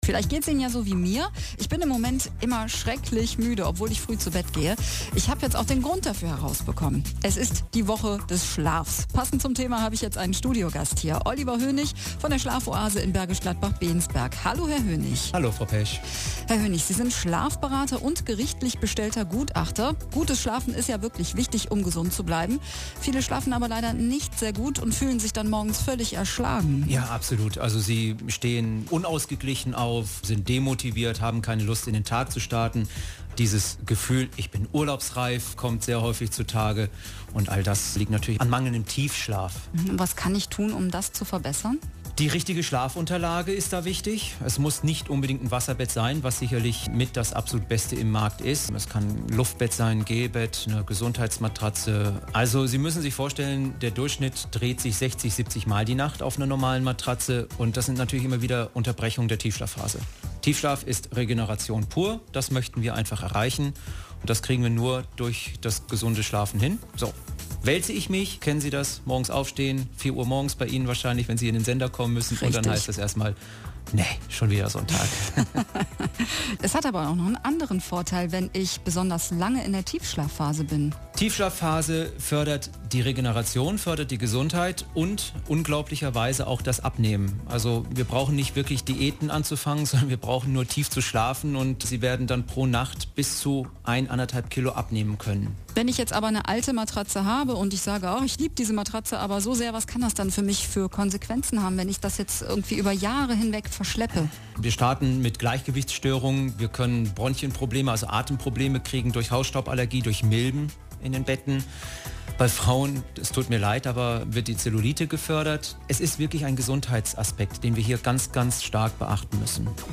Radiowerbung Woche des Schlafes, Variante 1 :